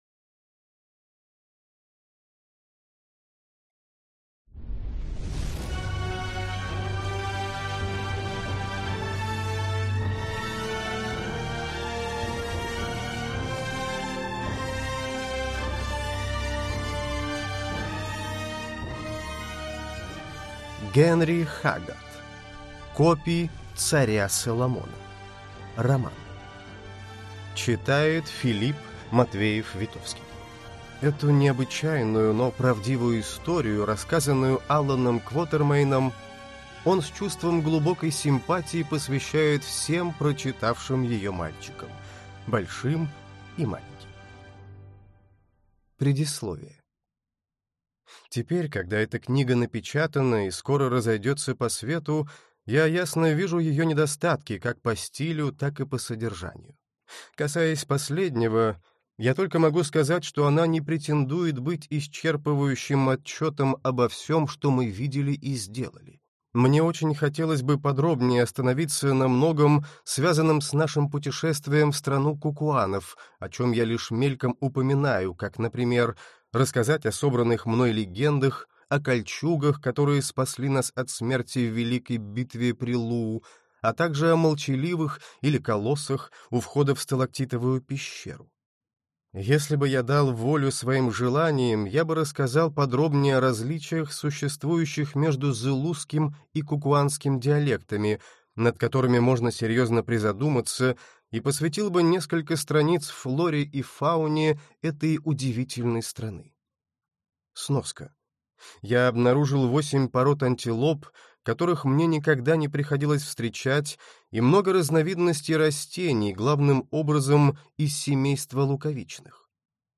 Аудиокнига Копи царя Соломона | Библиотека аудиокниг